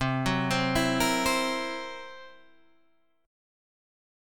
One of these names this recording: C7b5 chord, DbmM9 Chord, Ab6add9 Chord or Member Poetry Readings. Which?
C7b5 chord